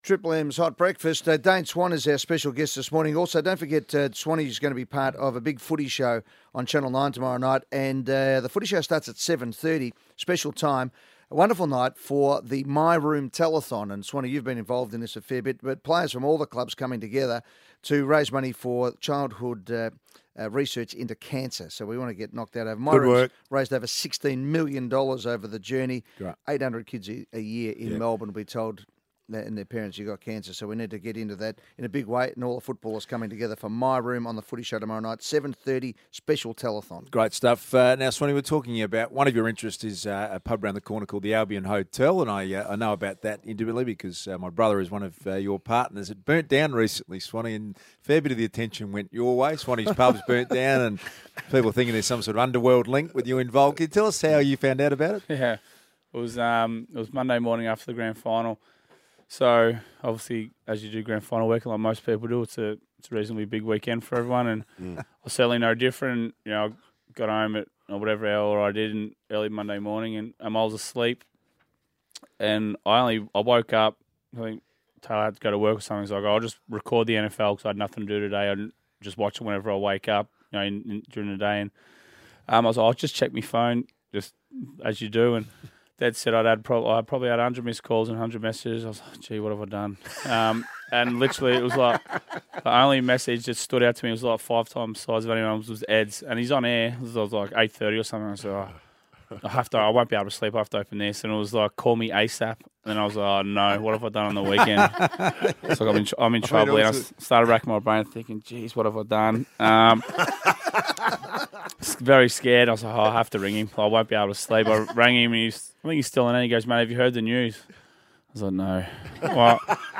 Listen as Dane Swan joins the Triple M Hot Breakfast team on the morning after his retirement from AFL football.